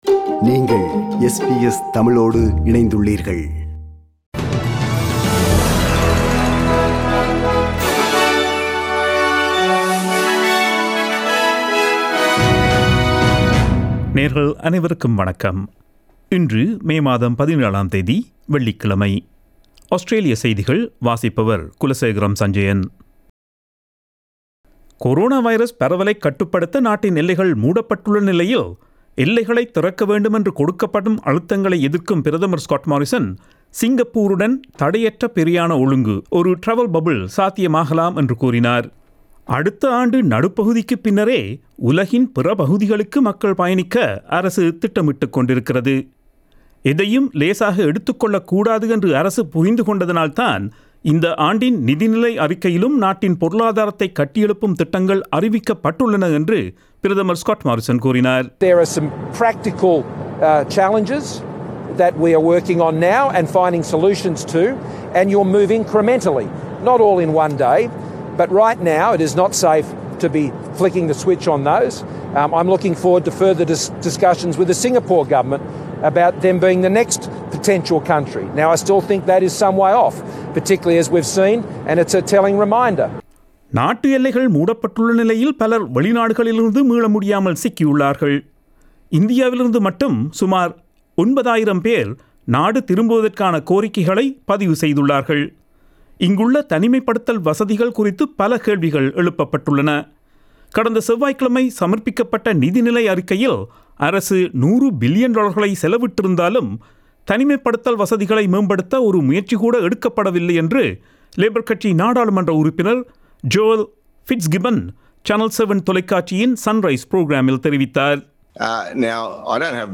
Australian news bulletin for Monday 17 May 2021.